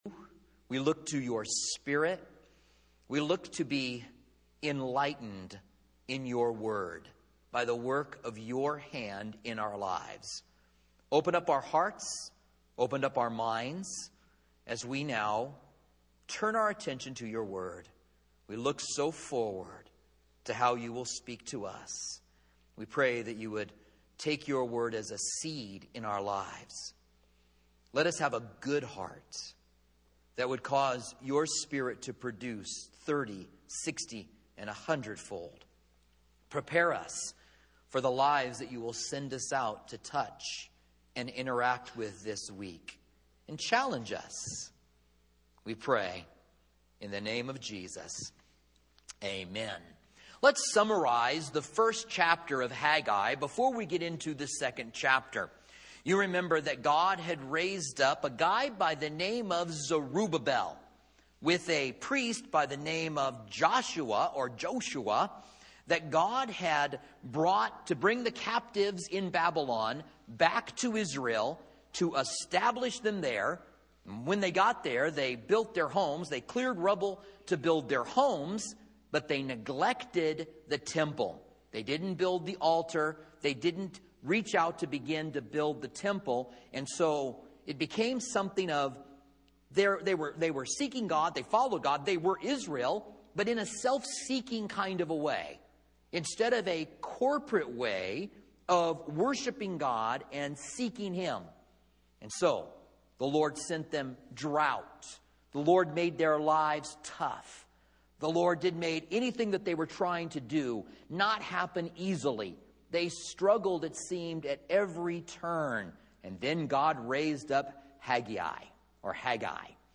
Commentary on Haggai